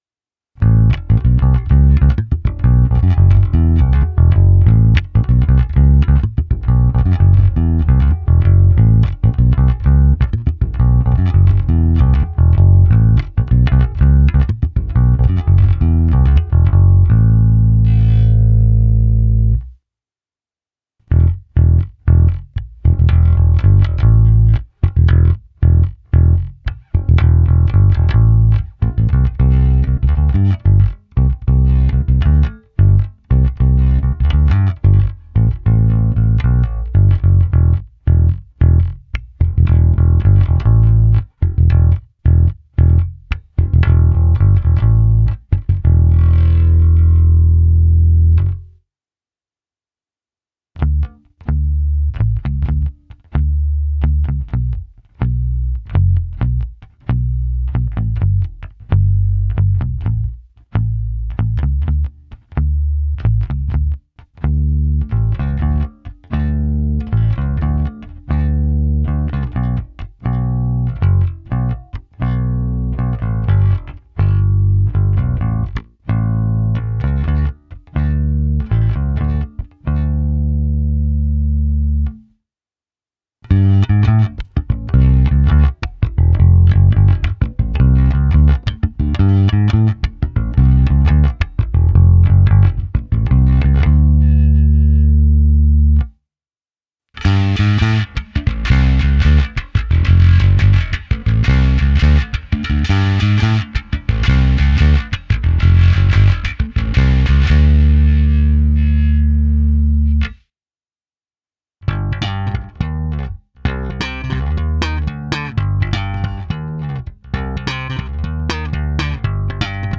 Plné basy tmelící kapelu, výrazné středy, které basu prosadí v mixu, příjemné výšky potřebné pro zkreslení, slap a vyhrávky.
Nahrávka se simulací aparátu, kde bylo použita hra prsty, trsátkem tlumeně a netlumeně, pak ukázka se zkreslením, a nakonec hra slapem.
Simulace aparátu FW